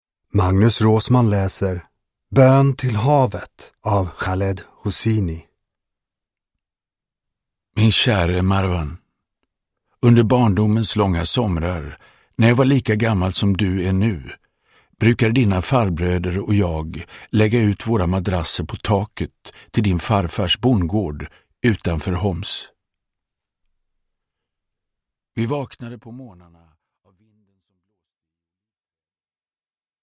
Bön till havet – Ljudbok – Laddas ner